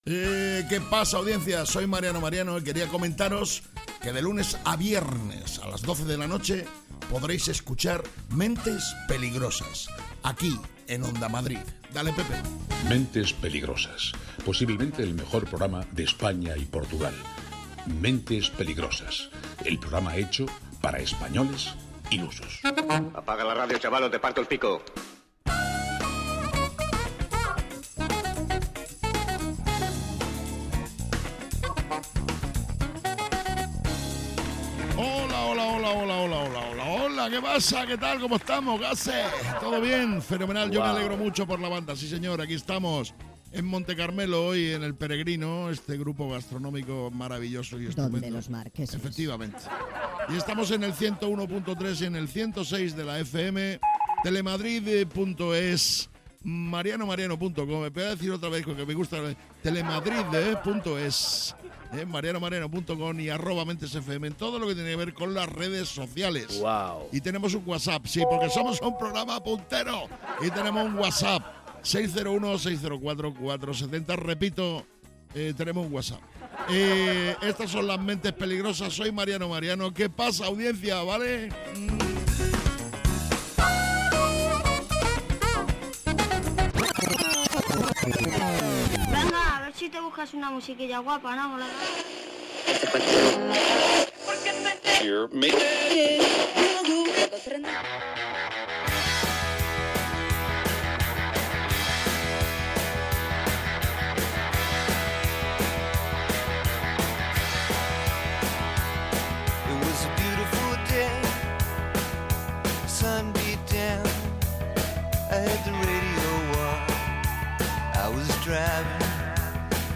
¿Es un programa de humor?